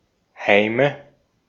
Ääntäminen
Ääntäminen Tuntematon aksentti: IPA: /haɪ̯mə/ Haettu sana löytyi näillä lähdekielillä: saksa Käännöksiä ei löytynyt valitulle kohdekielelle. Heime on sanan Heim monikko.